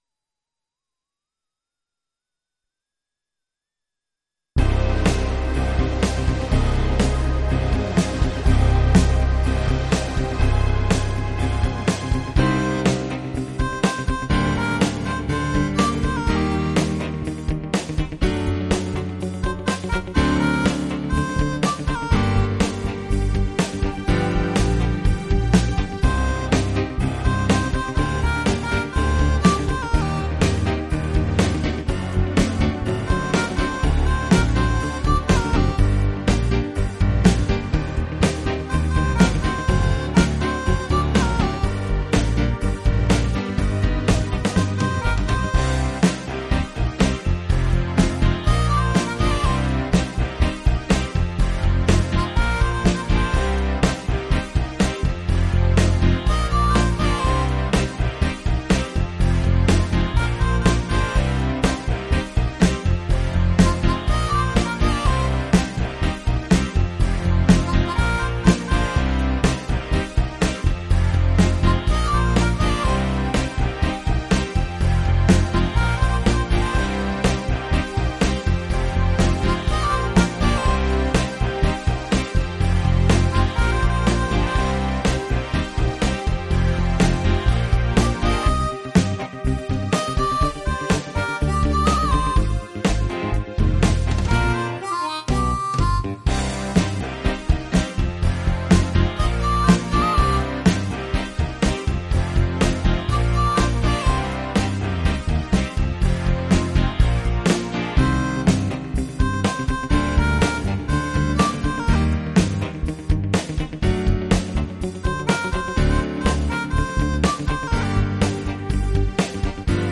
multi-track instrumentale versie